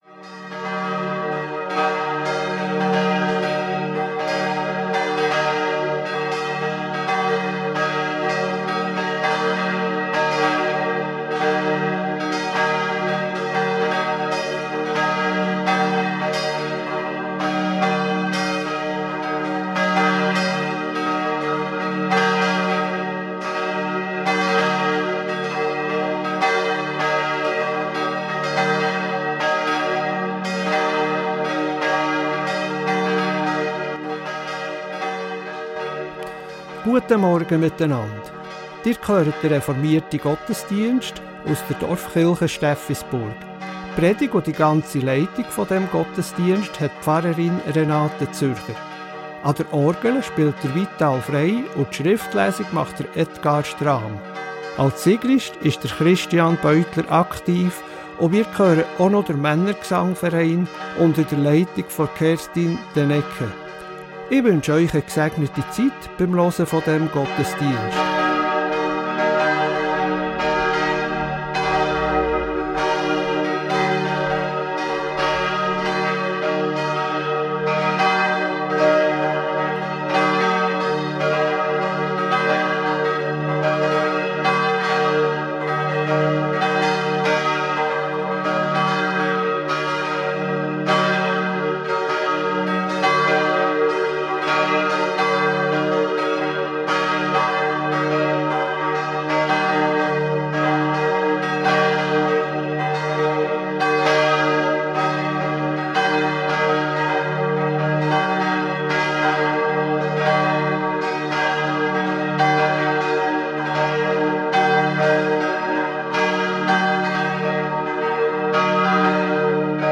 Reformierte Dorfkirche Steffisburg ~ Gottesdienst auf Radio BeO Podcast